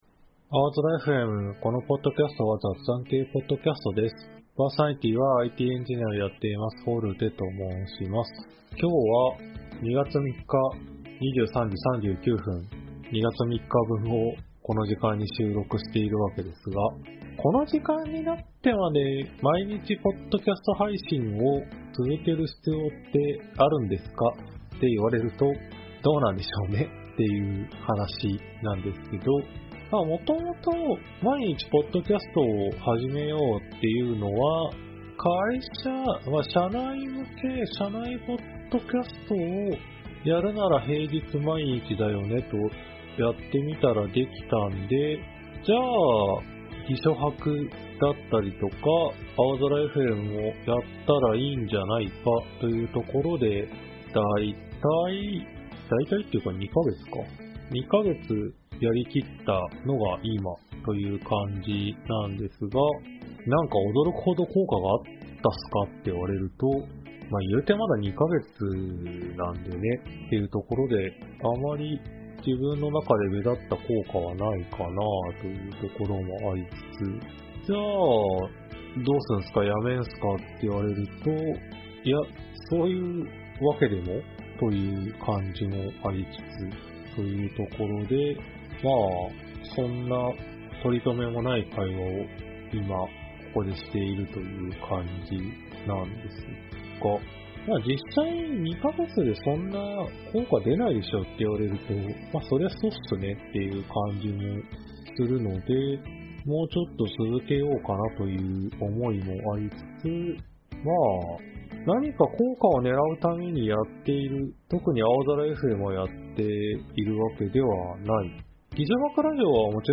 aozora.fmは仕事や趣味の楽しさを共有する雑談系Podcastです。